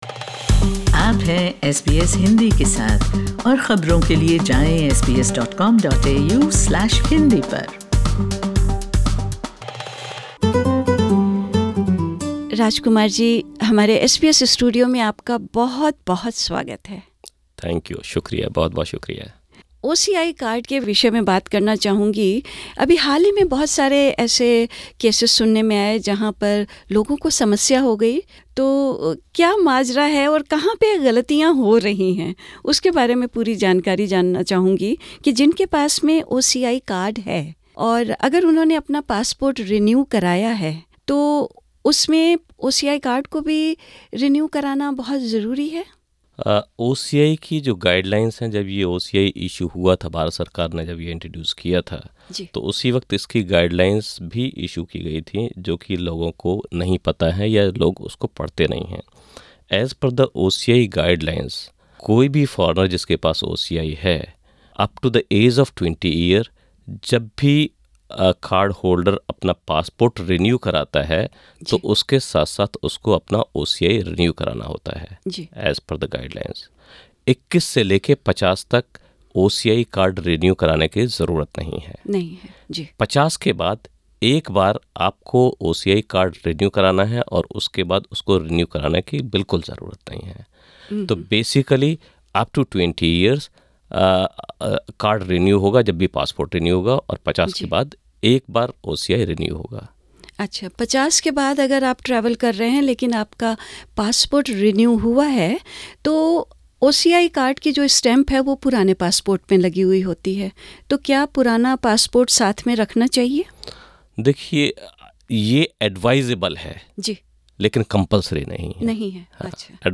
SBS Hindi spoke to Consul General of India in Melbourne, Mr Raj Kumar who explained in detail who needs to and who doesn't need to renew their Overseas Citizen of India (OCI) card.